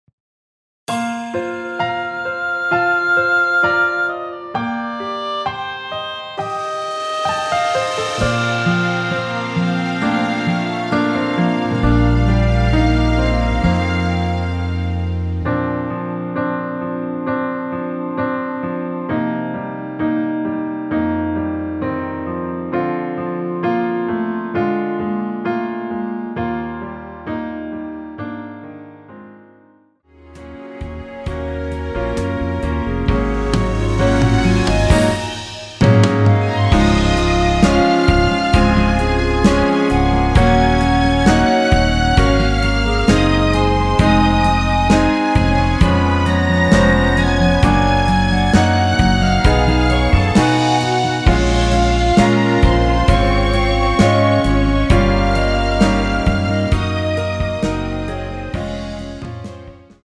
원키 멜로디 포함된 MR 입니다.(미리듣기 참조)
Bb
앞부분30초, 뒷부분30초씩 편집해서 올려 드리고 있습니다.
중간에 음이 끈어지고 다시 나오는 이유는
(멜로디 MR)은 가이드 멜로디가 포함된 MR 입니다.